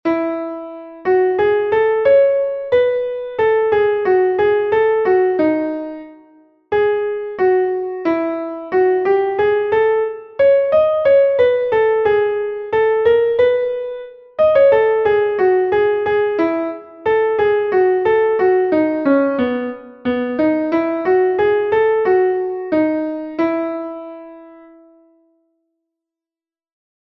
Entoación a capella